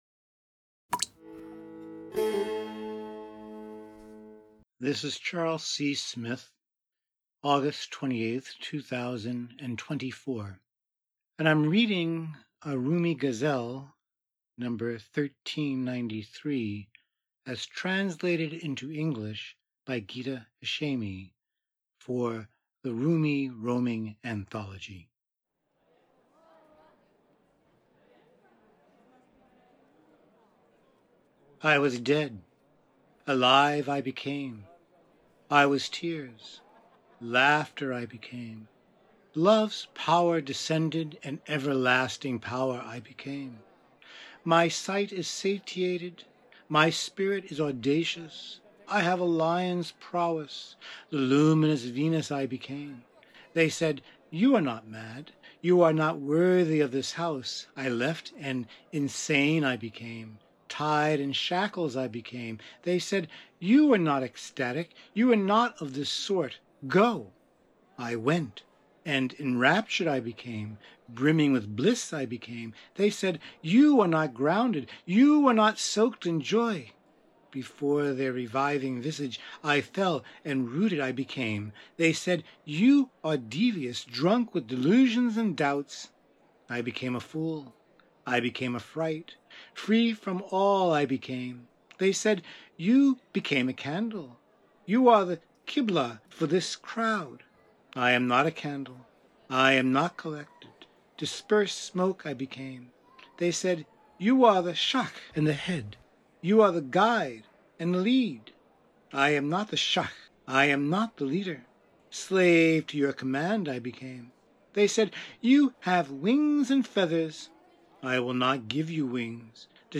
Rumi, Ghazal 1393, Translation, Rumi roaming, Poetry